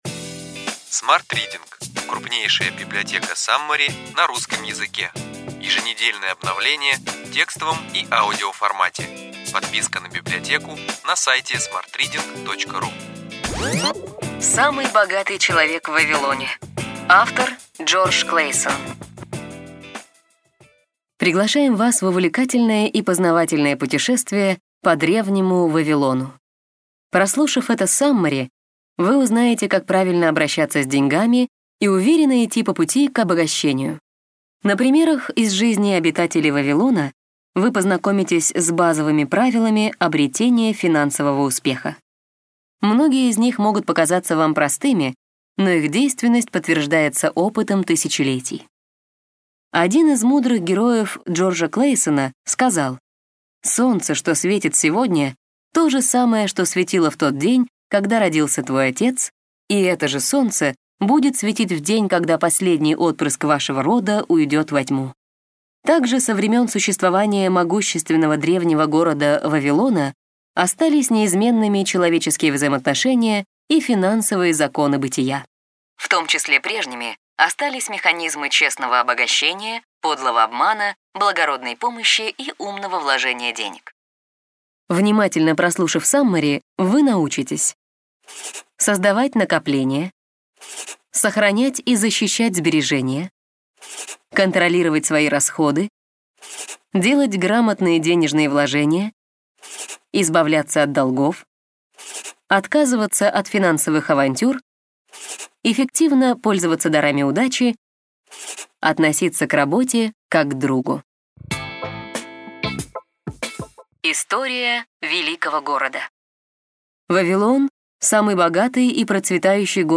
Аудиокнига Ключевые идеи книги: Самый богатый человек в Вавилоне. Джордж Клейсон | Библиотека аудиокниг